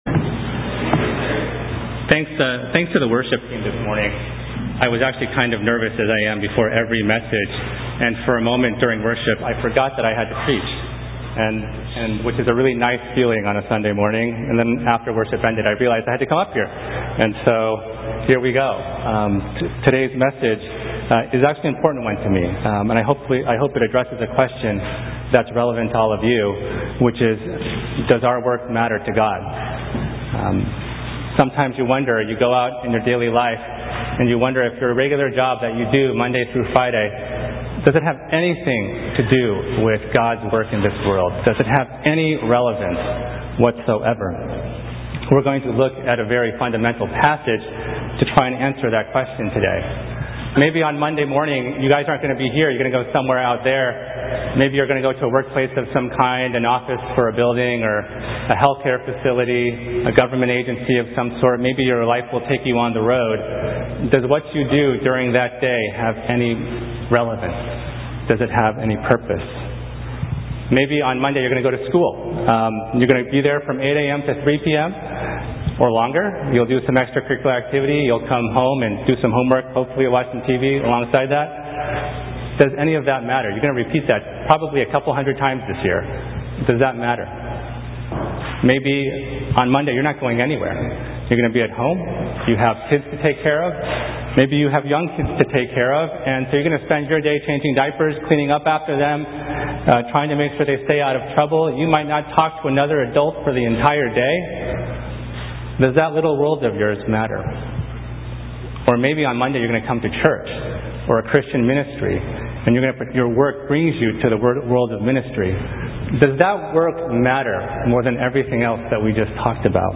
Categories: Newton English Service (11:00am)Tags: